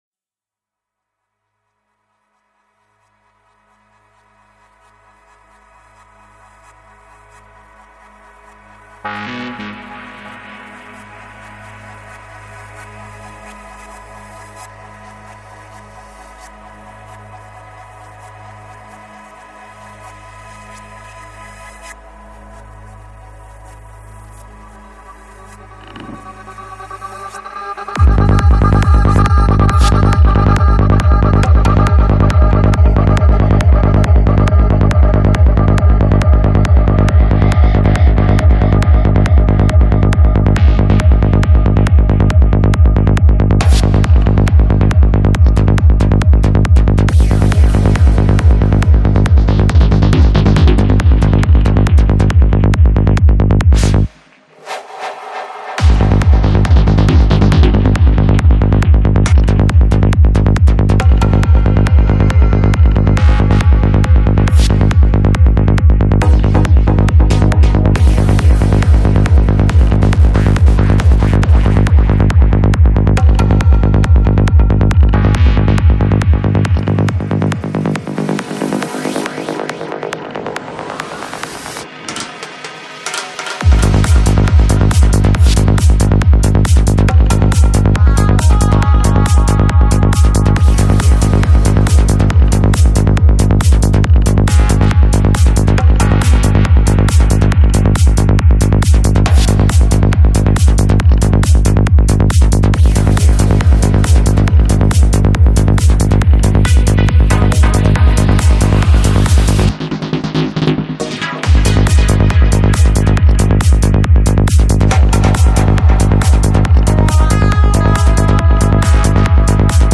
Жанр: Psychedelic
Psy-Trance Скачать 7.07 Мб 0 0 0